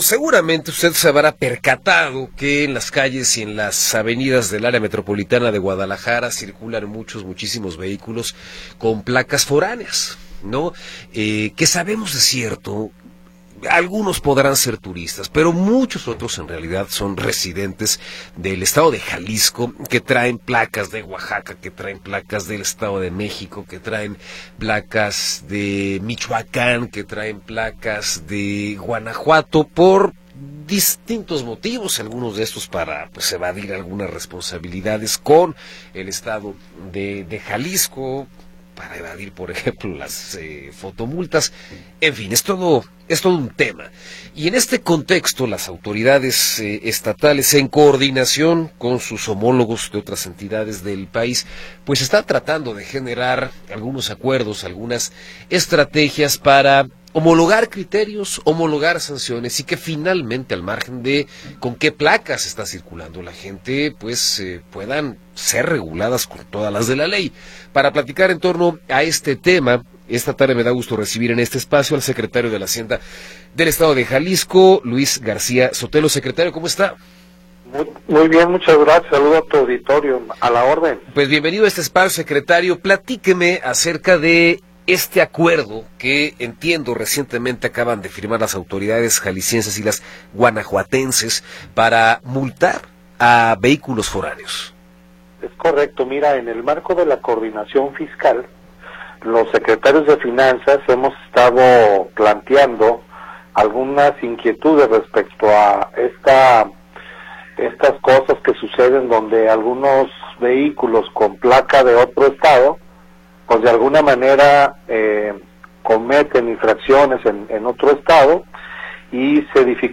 Entrevista con Luis García Sotelo